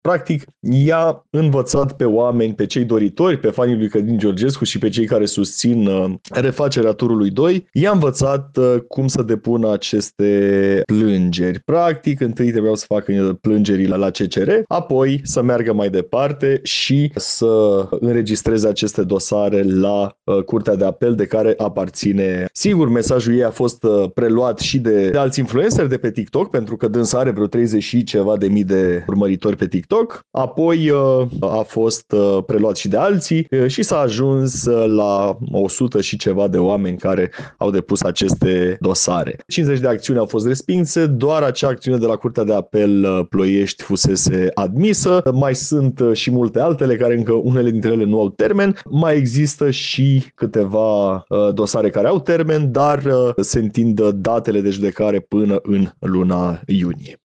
Într-o declarație pentru postul nostru de radio